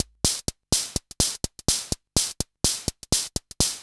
Index of /musicradar/retro-house-samples/Drum Loops
Beat 21 No Kick (125BPM).wav